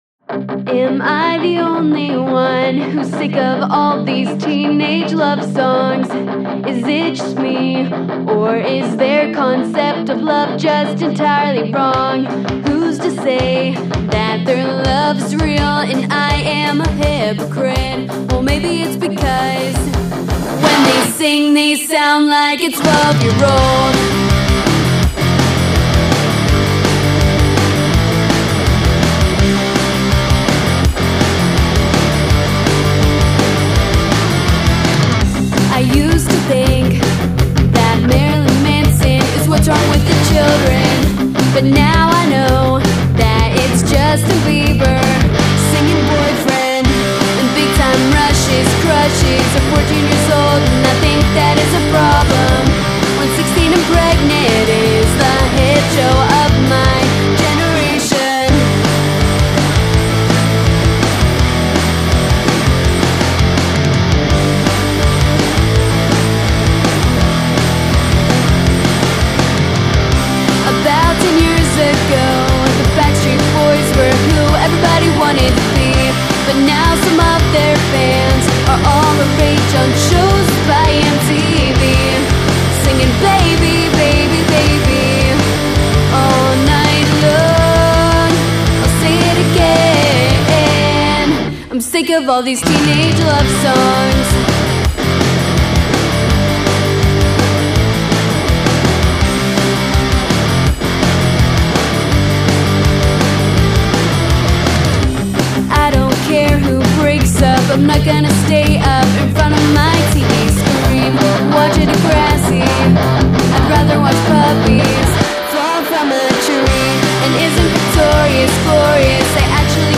This is my first commercial mix ever.